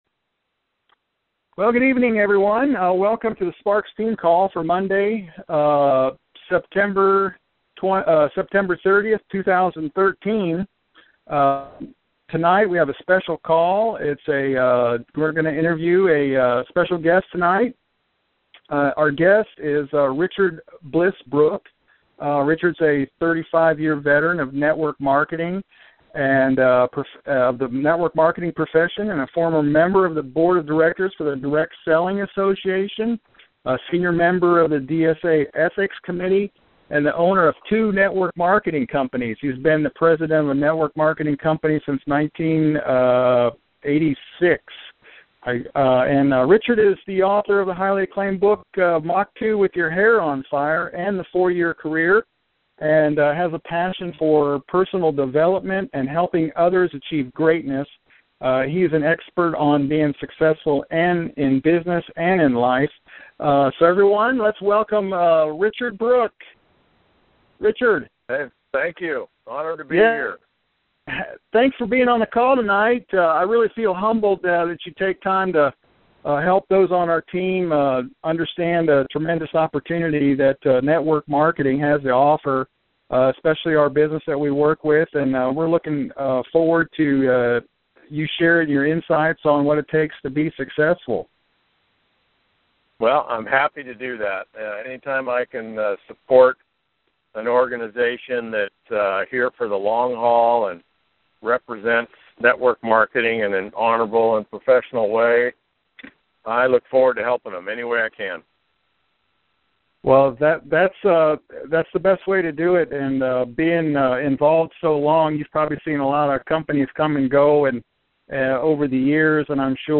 In our weekly AMSOIL Dealer training call I interview